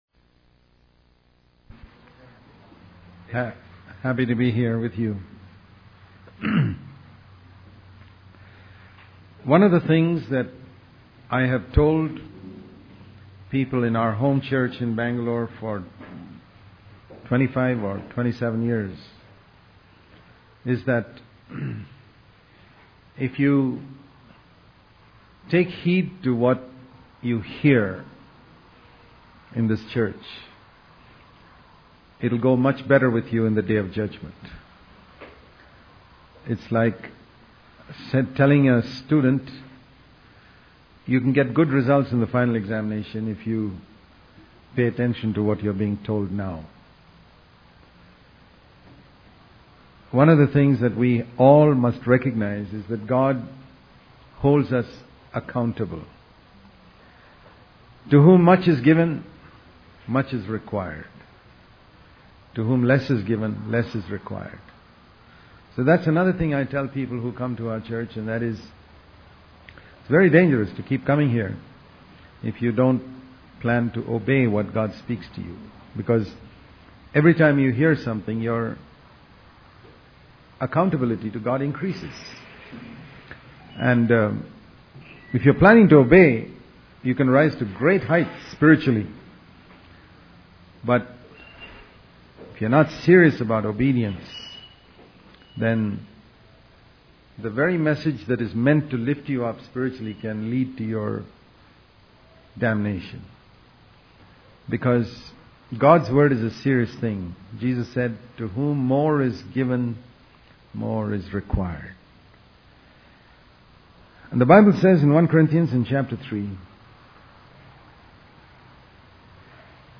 In this sermon, the speaker emphasizes the importance of following the examples set by Jesus and the apostles in the Bible. He criticizes the focus on glory and success in modern Christianity, stating that it is far removed from the teachings in the Acts of the Apostles. The speaker urges listeners to ensure that their work originates from listening to God and warns of the accountability we have to Him.